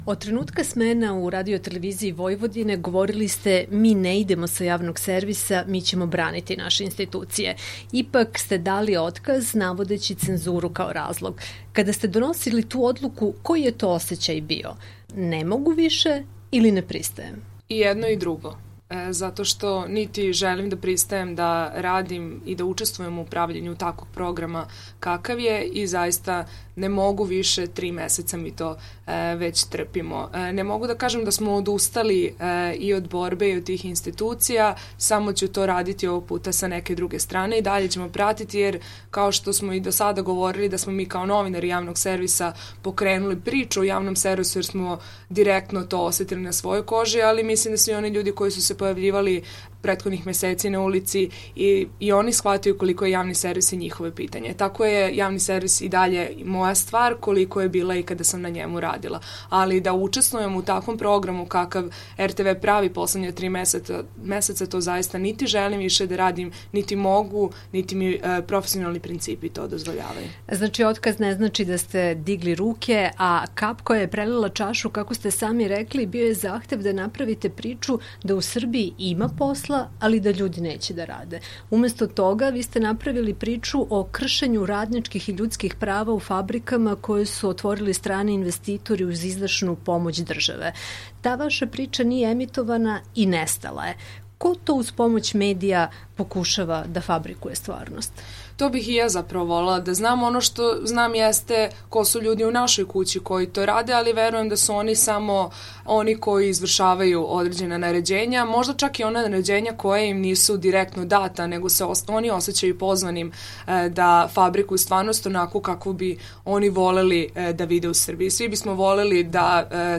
Intervju nedelje